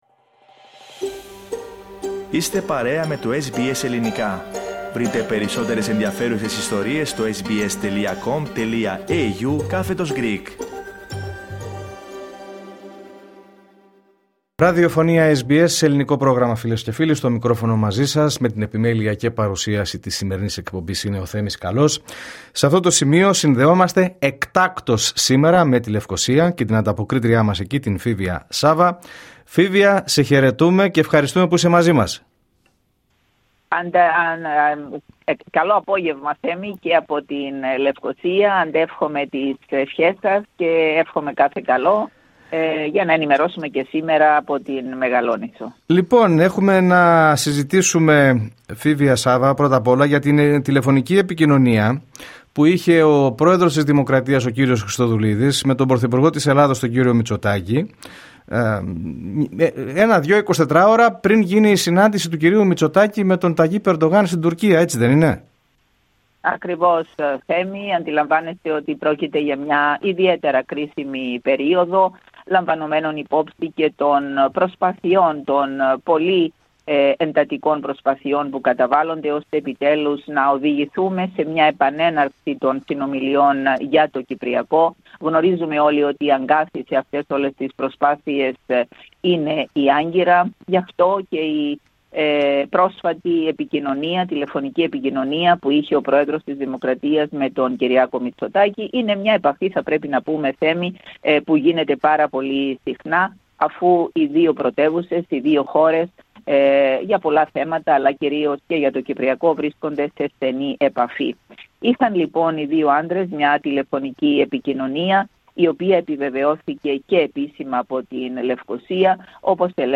Ακούστε αναλυτικά την ανταπόκριση από την Κύπρο πατώντας PLAY δίπλα από την κεντρική εικόνα.